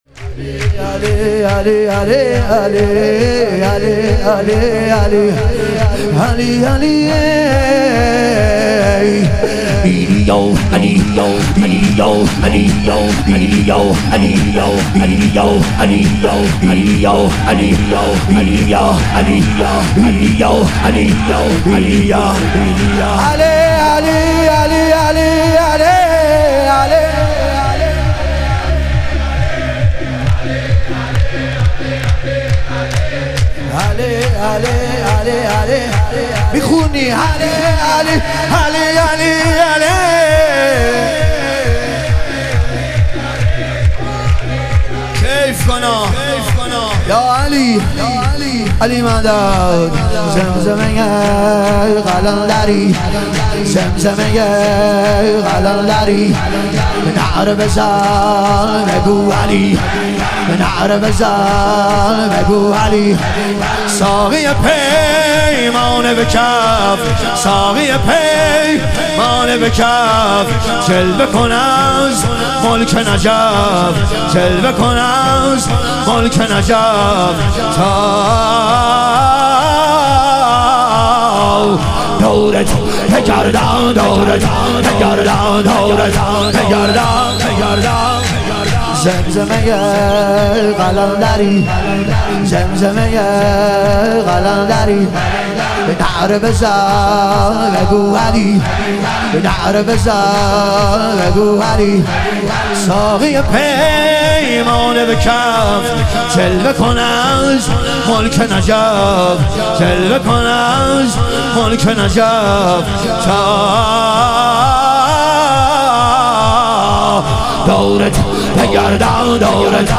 ظهور وجود مقدس امام جواد و حضرت علی اصغر علیهم السلام - شور